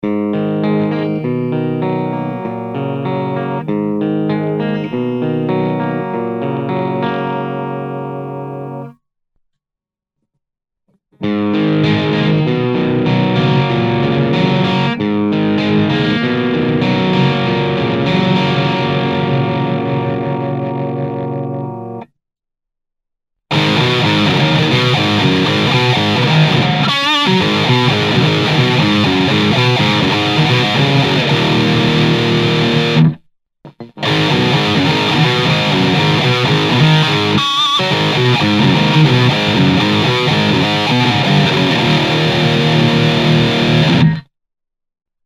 オーバドライブはGAIN7 Bass6 Middle6 Treble7プリアンプブースターON時はGAIN5
クリーンはGAIN4 Bass５ Treble5
ピックアップはEMG81です。
ゲインは高くエッジはある歪みです。
低音域やハイゲインをとるならこれかな？